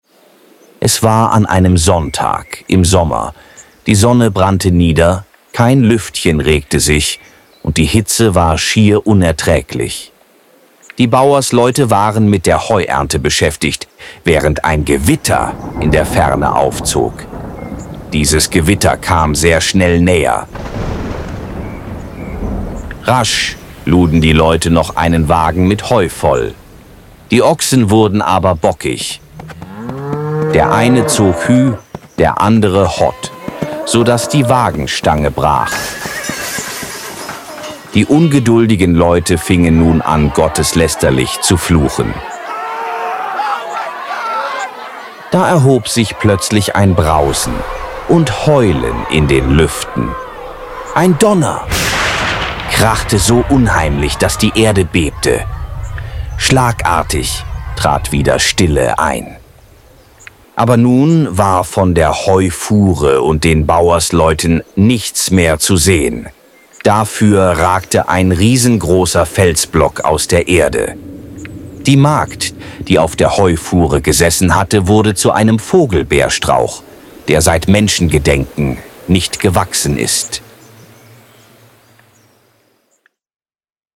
Erlebnispunkt 3 – Versteinerte Heufuhre Audioguide – Sage